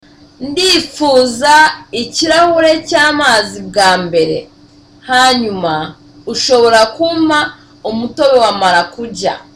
(Softly)